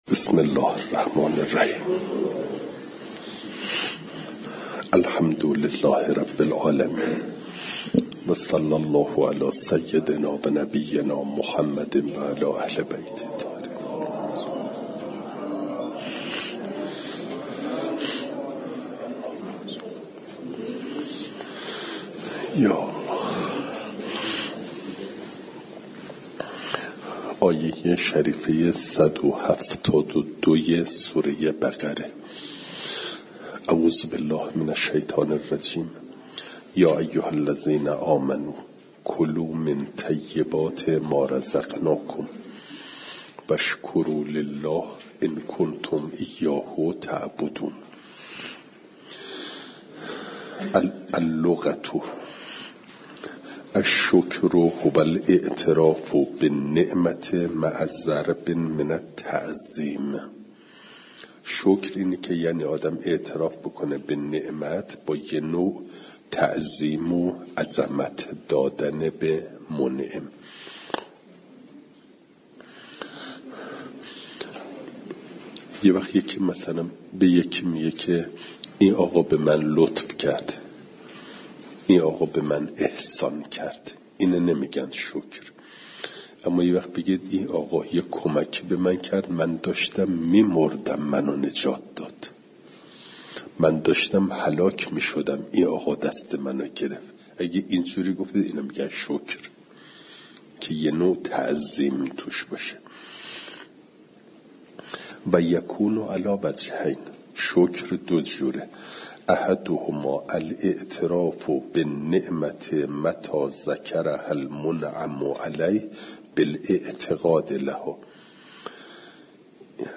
فایل صوتی جلسه صد و شصت و هشتم درس تفسیر مجمع البیان